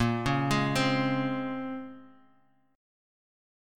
A#m9 Chord